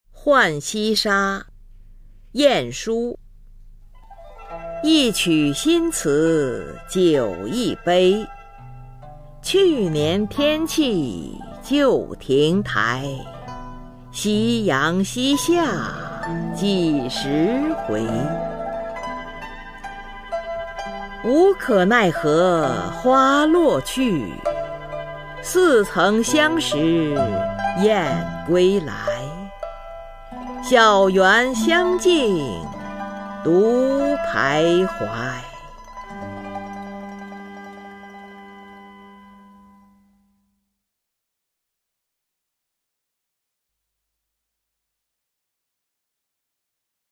[宋代诗词诵读]晏殊-浣溪沙 宋词朗诵